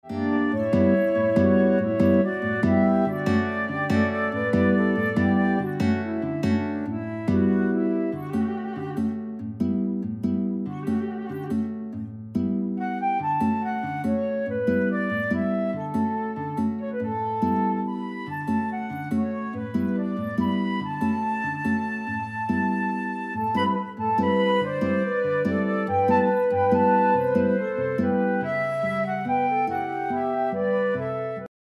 Für 2 Gitarren und Flöte
Jazz/Improvisierte Musik
Ensemblemusik
Trio
Flöte (2), Gitarre (1)